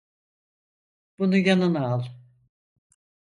Pronounced as (IPA) /ɑɫ/